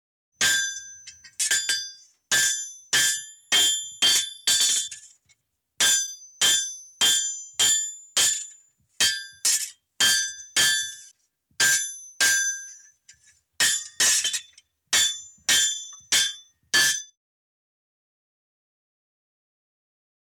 Звуки фехтования
Звук схватки на мечах двух мушкетеров